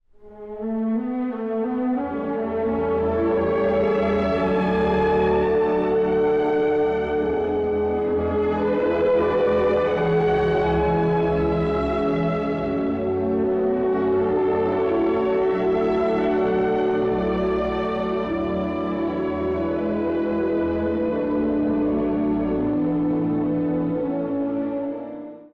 （序奏） 古い音源なので聴きづらいかもしれません！（以下同様）
ビオラの音色につられ、一面の大地が目覚めるように始まります。